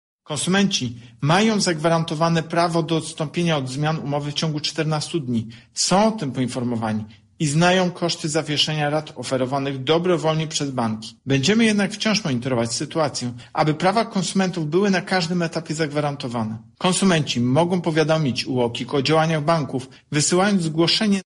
Chcemy wyeliminować niejasne zapisy w umowach – mówi prezes UOKiK Tomasz Chróstny: